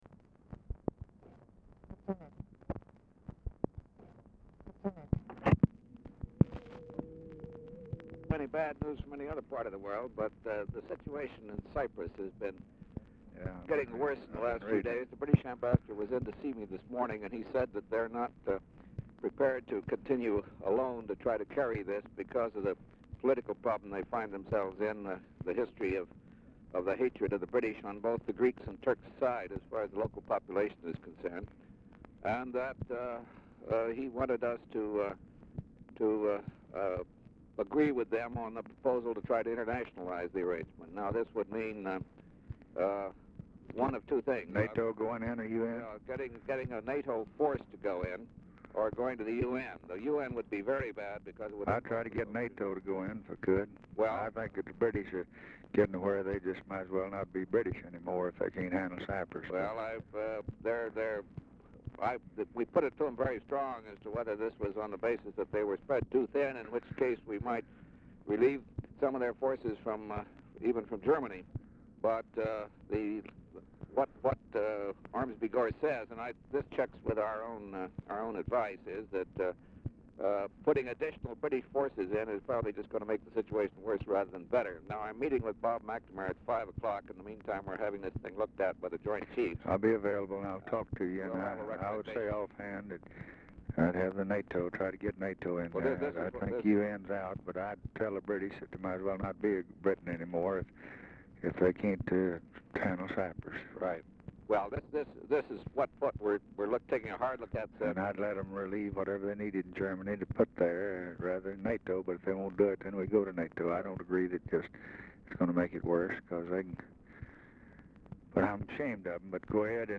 Telephone conversation # 1543, sound recording, LBJ and GEORGE BALL, 1/25/1964, 2:05PM | Discover LBJ
Format Dictation belt
Location Of Speaker 1 Oval Office or unknown location
Specific Item Type Telephone conversation